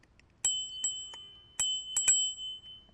铃声、蜂鸣声、信号记录 " 05969 普通自行车铃声
描述：常见的自行车铃 干音